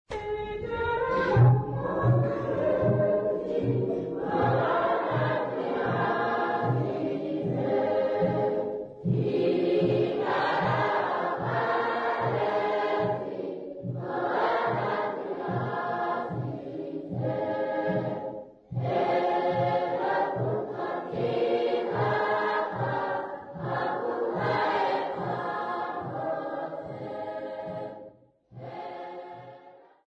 Folk music
Sacred music
Field recordings
Africa Namibia Doringveld sx
Indigenous Ovambo music accompanied by clapping
96000Hz 24Bit Stereo